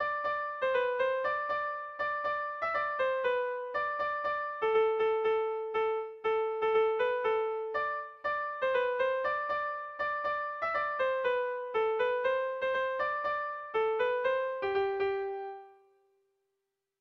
Sentimenduzkoa
Pantxoa Eta Peio
ABAD